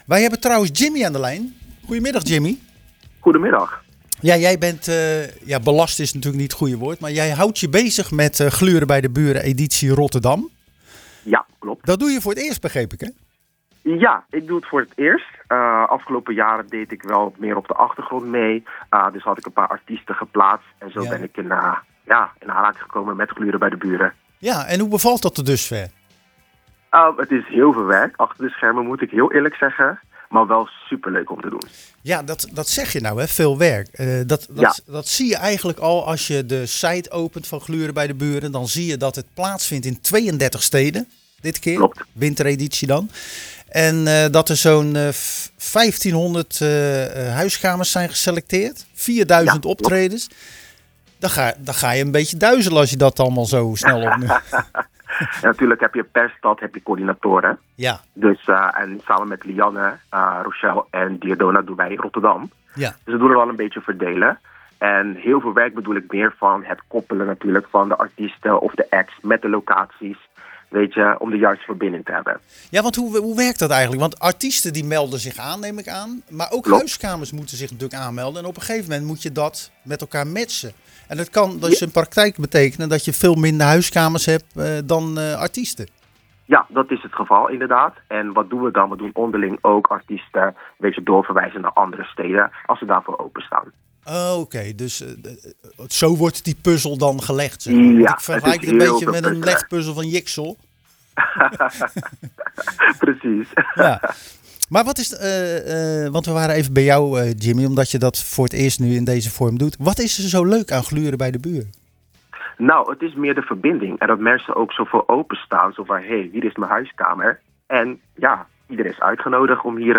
Tijdens de wekelijkse uitzending van Zwaardvis spraken we met de organisatie van Gluren Bij De Buren editie Rotterdam.